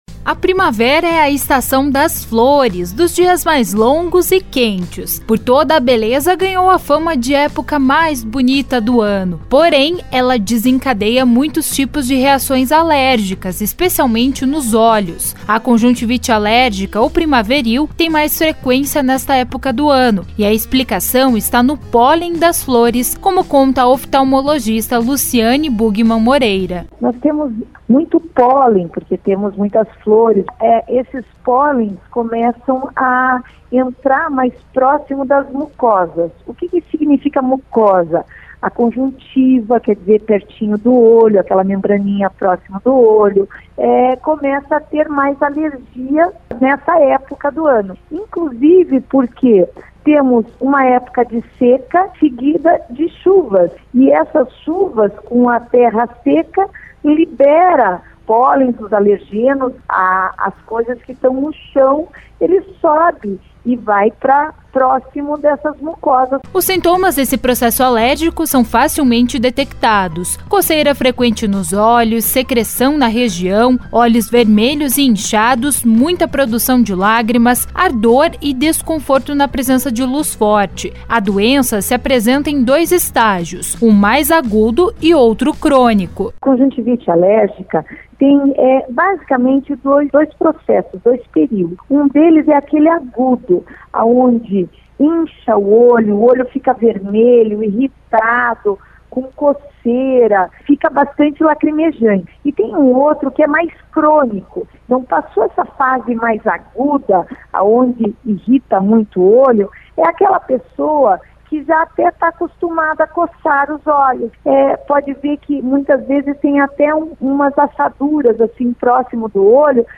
Reportagem 02- Conjuntivite